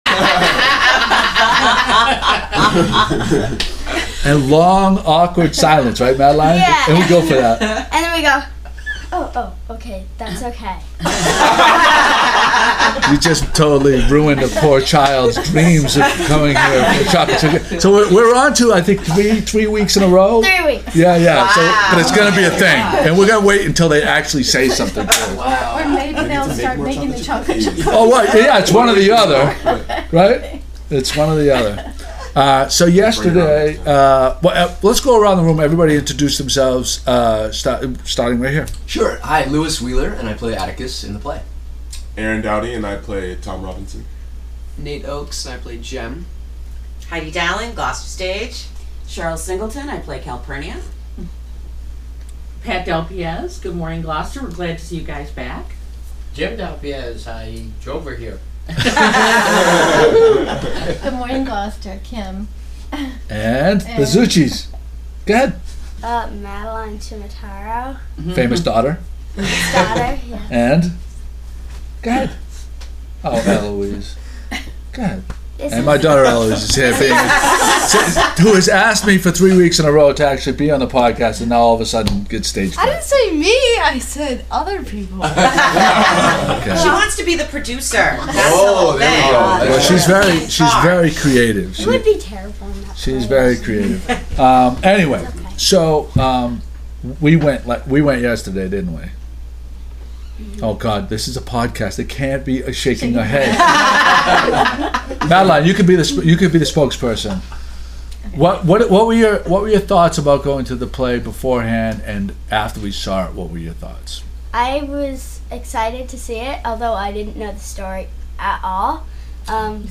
There is a minute and a half break in the audio I couldn’t correct from 34:57- 36:25 Full audio here with the intro